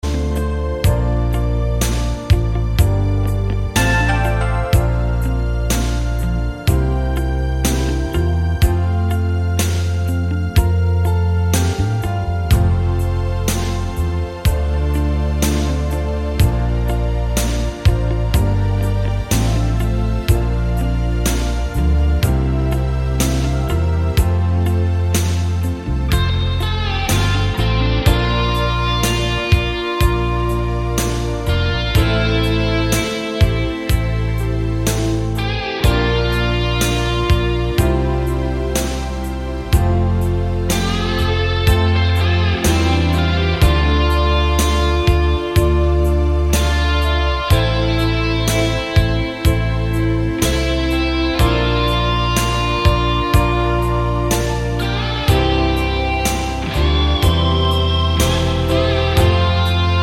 no Backing Vocals Reggae 5:00 Buy £1.50